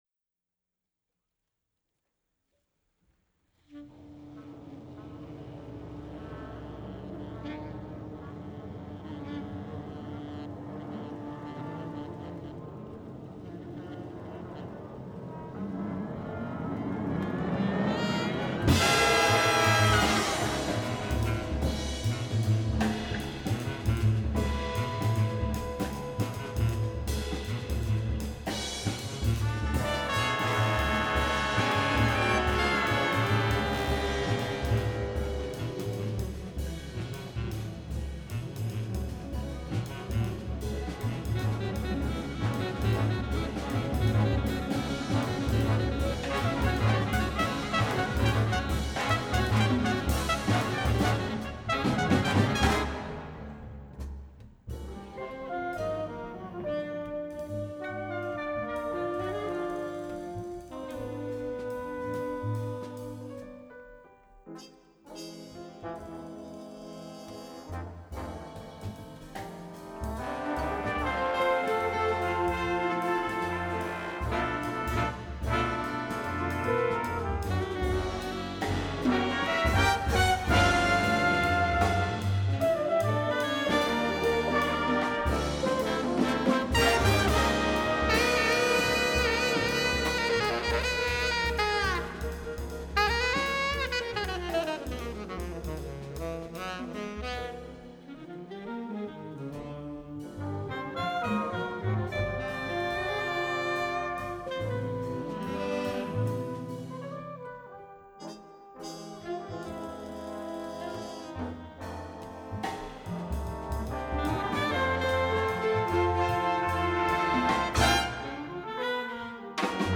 Big Band (9 Brass). Medium Swing.